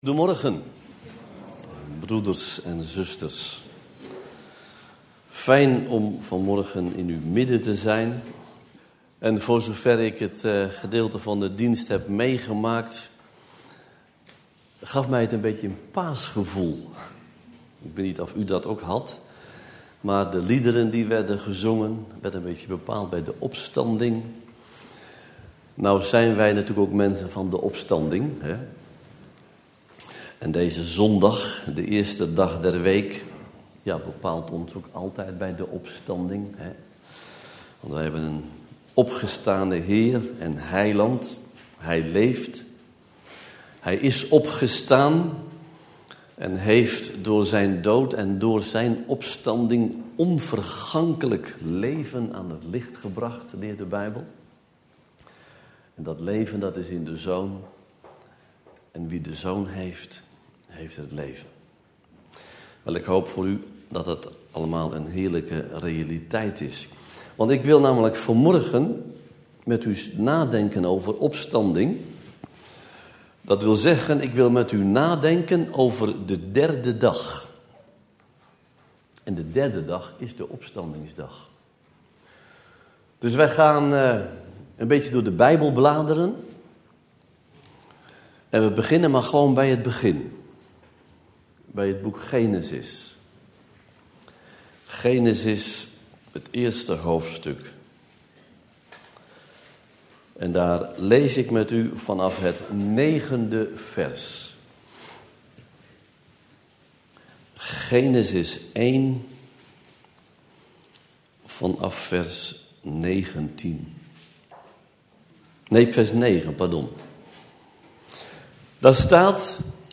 In de preek aangehaalde bijbelteksten (Statenvertaling)Genesis 1:9-139 En God zeide: Dat de wateren van onder den hemel in een plaats vergaderd worden, en dat het droge gezien worde!